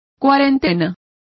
Complete with pronunciation of the translation of quarantines.